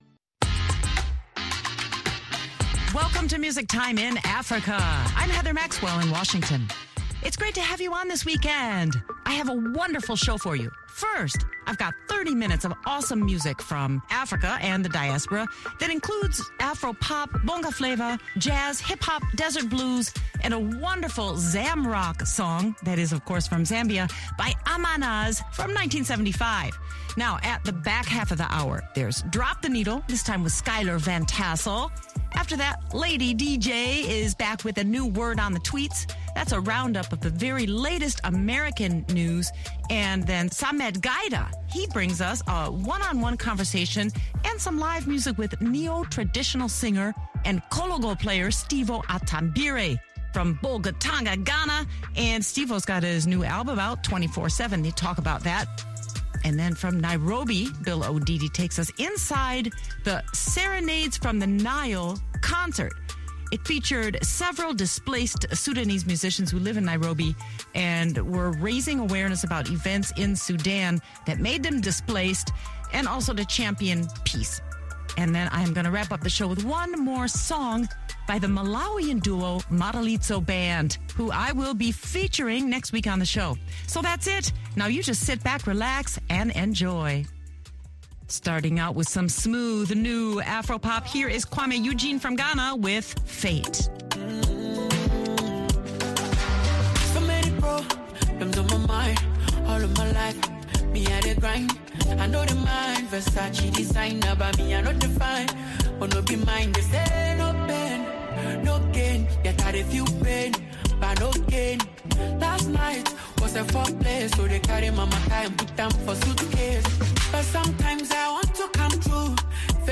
The 30-minute playlist includes afropop, bonga flava, jazz, hip-hop, desert blues, and vintage Zamrock.